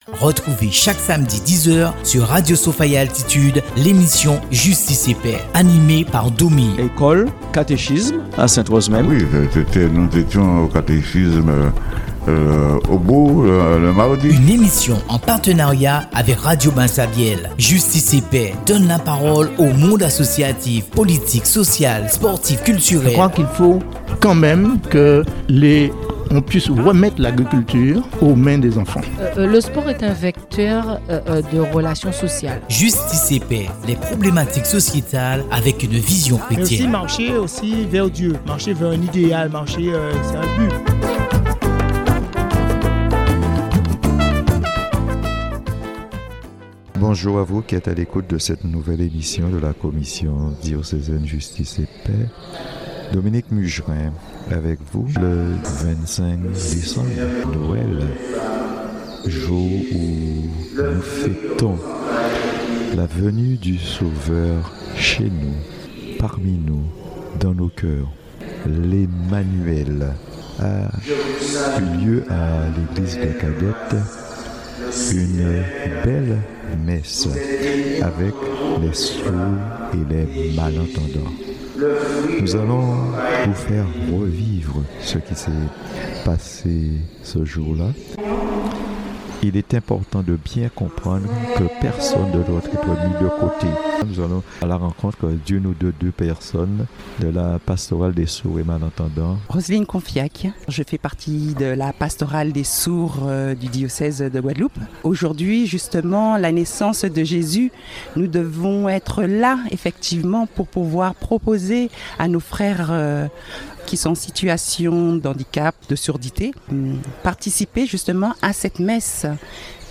Retour sur la messe de Noël de décembre 2025 avec nos frères sourds et malentendants dans la paroisse de Cadet Notre Dame du Rosaire.